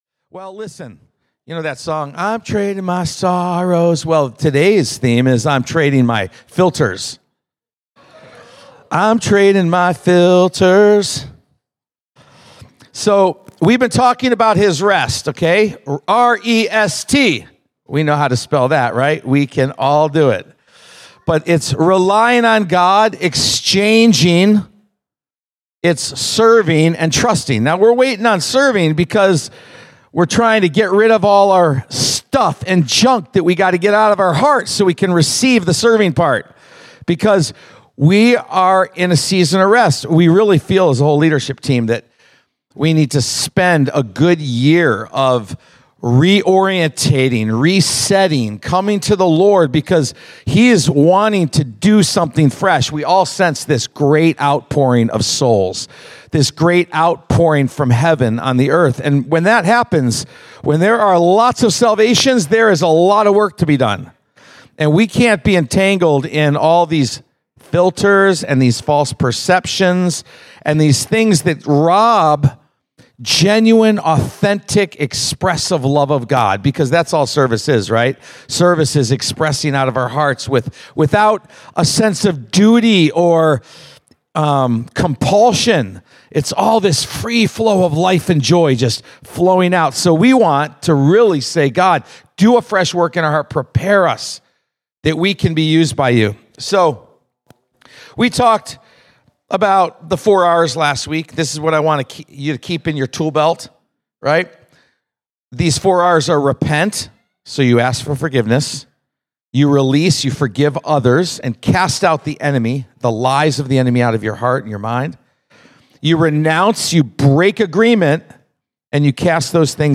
1.12.25-Sunday-Service.mp3